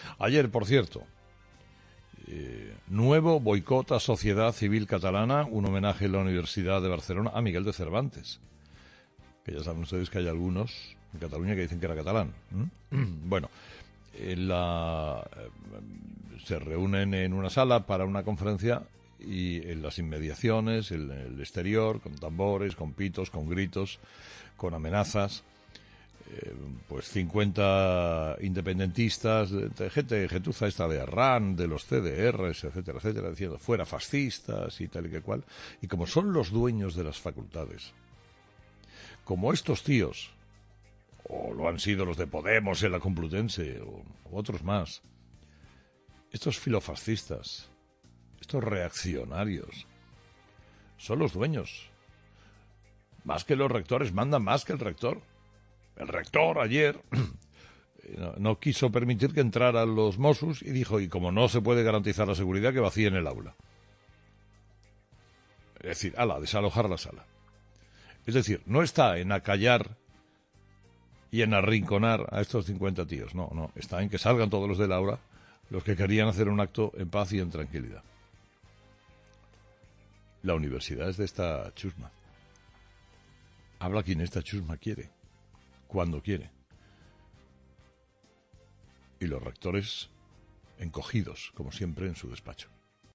"Habla quien esta chusma quiere y cuando quiere. Y los rectores, como siempre encogidos en su despacho", ha dicho el comunicador en su monólogo de este viernes
En su monólogo de este viernes, Carlos Herrera no ha dudado en entrar a valorar este último ataque a Sociedad Civil y a quienes defienden la Constitución en España.